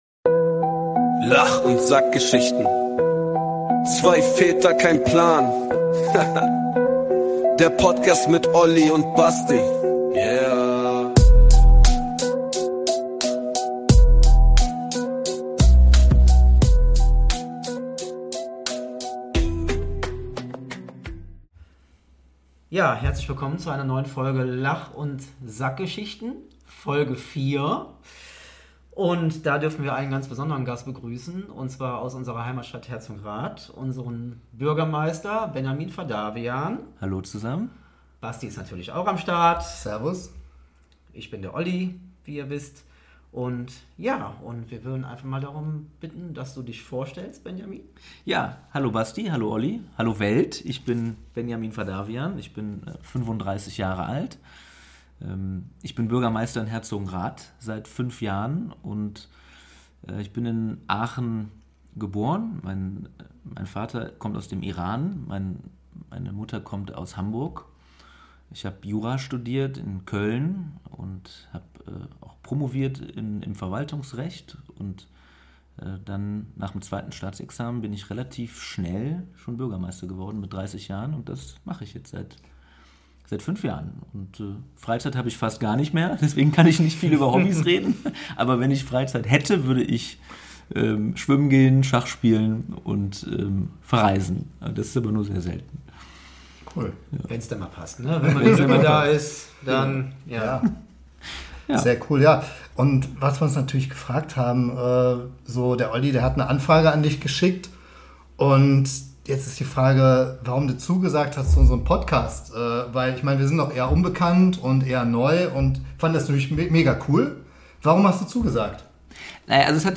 Im Talk: Bürgermeister Benjamin Fadavian ~ Lach und Sackgeschichten Podcast
In Folge 4 unseres Podcasts haben wir einen ganz besonderen Gast. Es besuchte uns der Bürgermeister der Stadt Herzogenrath: Benjamin Fadavian. In lockerer Atmosphäre beantwortete er uns einige Fragen, die uns unter den Nägeln brannten und schnell kamen wir auch zum Bürgermeister Papa-Vergleich.